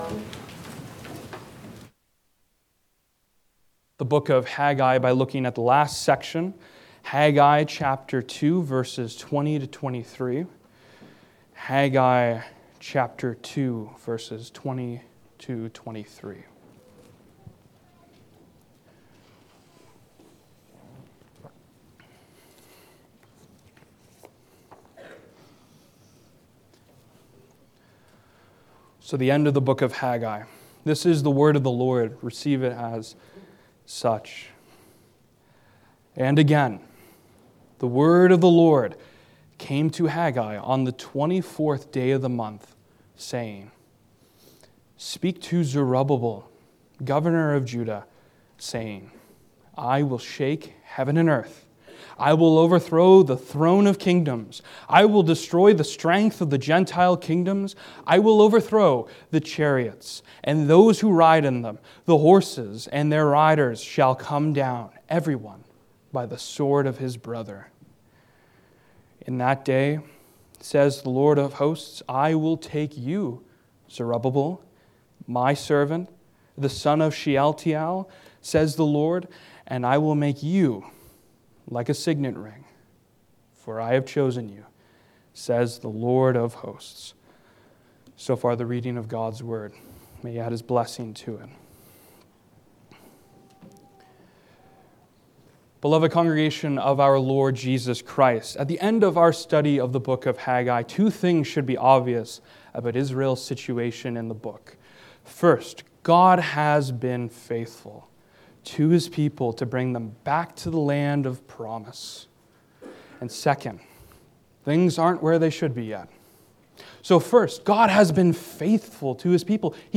Passage: Haggai 2:20-23 Service Type: Sunday Morning